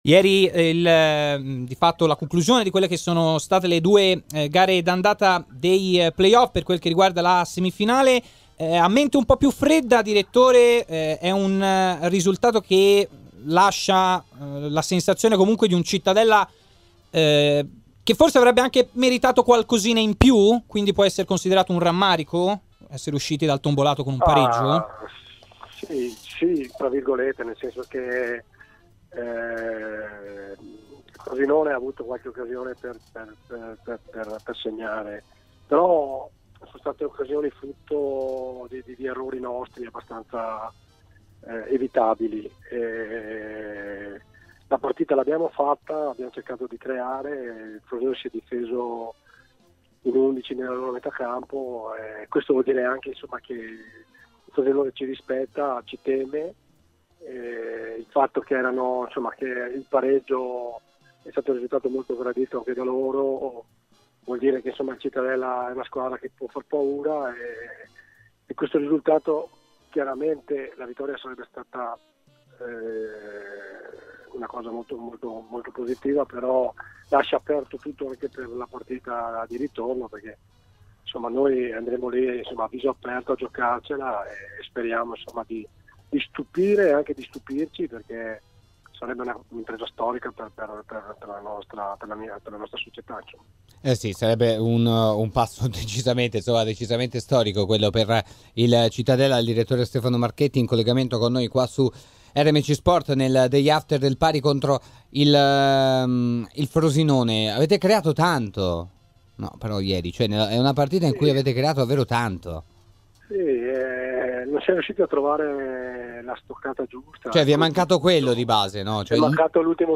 © registrazione di TMW Radio